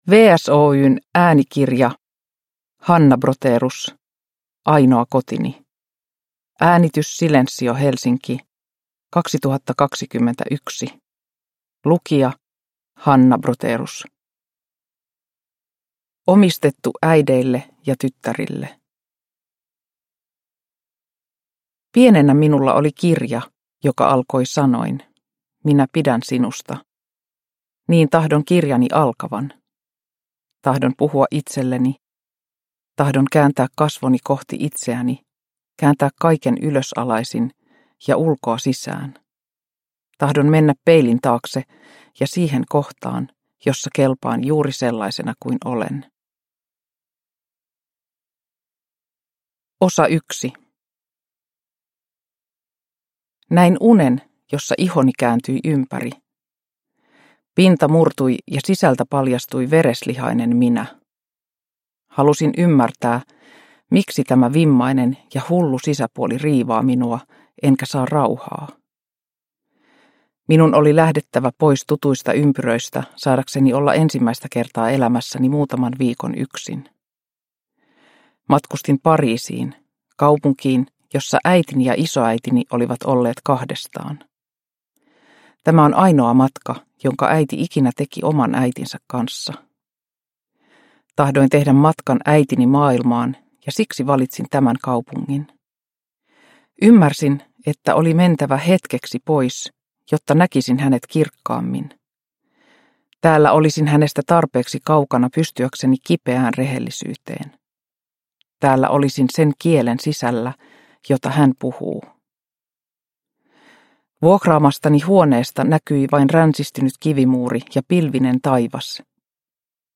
Ainoa kotini – Ljudbok